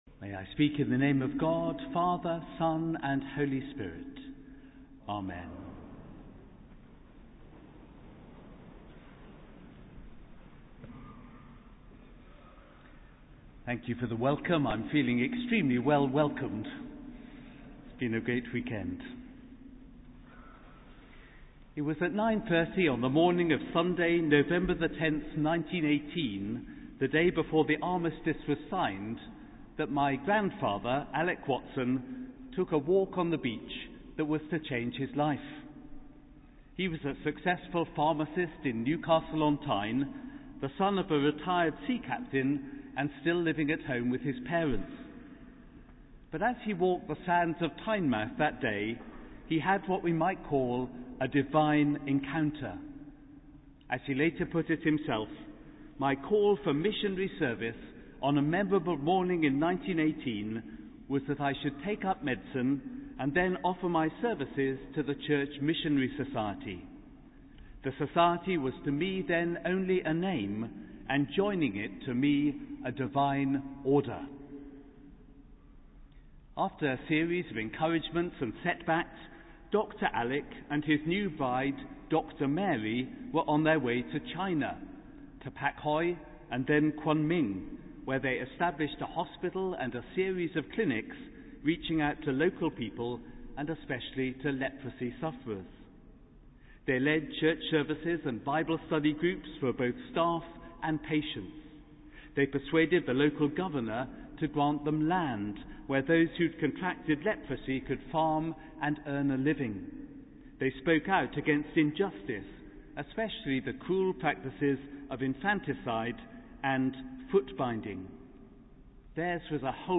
Sermon: Choral Evensong - 1 March 2015
Venue: Guildford Cathedral